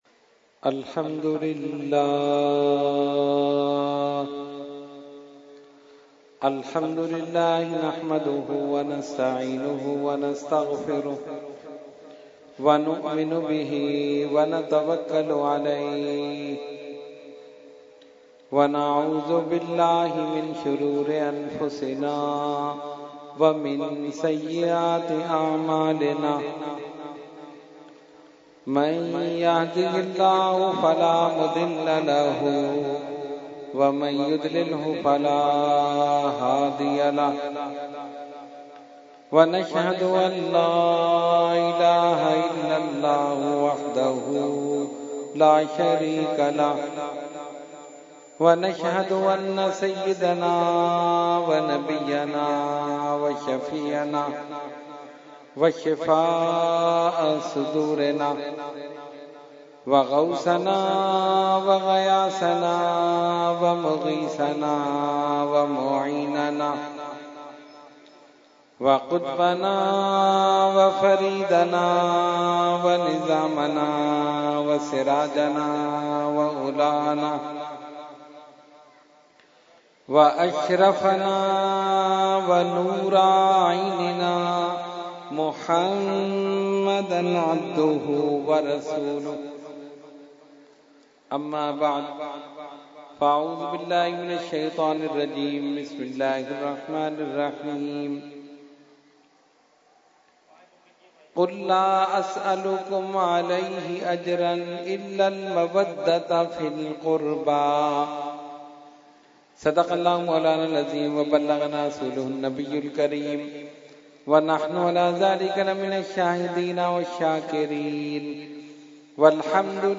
Category : Speech | Language : UrduEvent : Muharram 2018